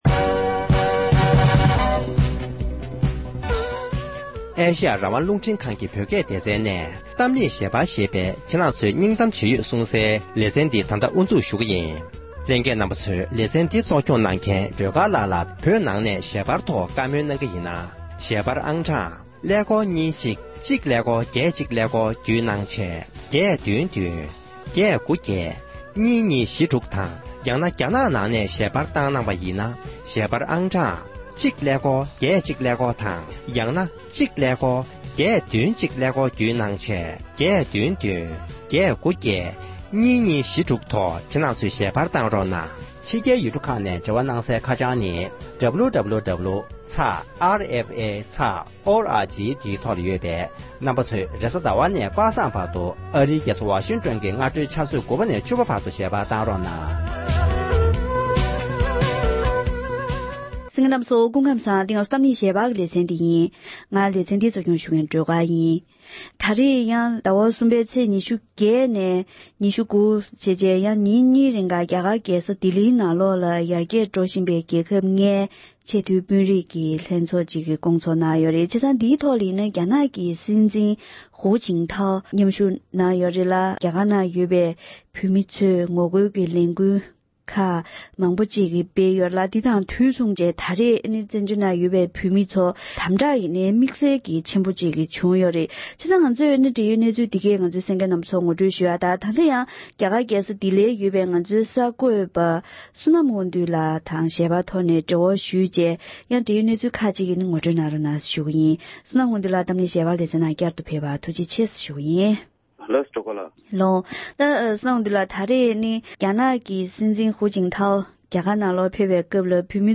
འབྲེལ་ཡོད་མི་སྣར་བཀའ་འདྲི་ཞུས་པ་ཞིག་ལ་གསན་རོགས་ཞུ༎